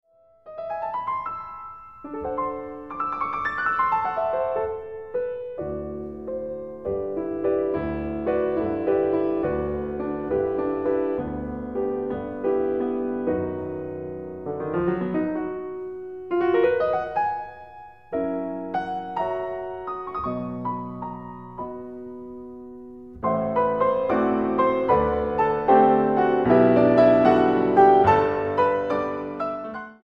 pianista.